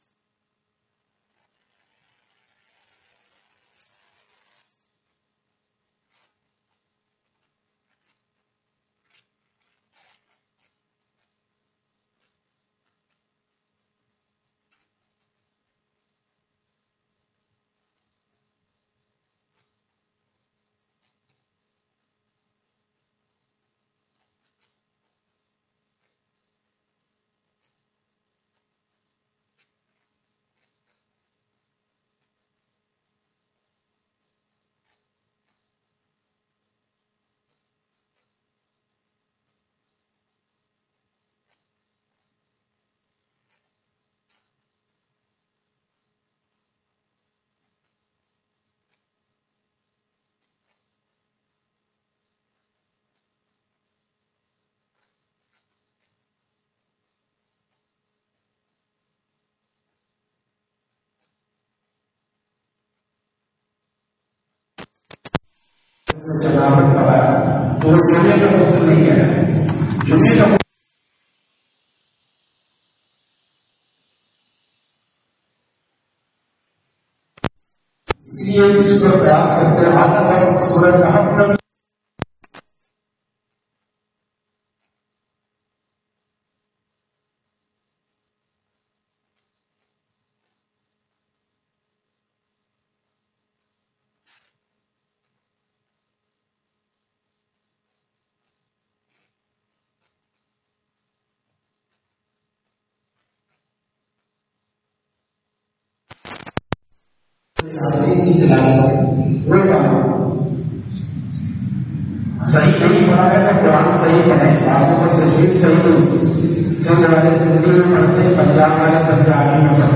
Bayan Befor Namaz e Jummah, Irafni Masjid Hyderabad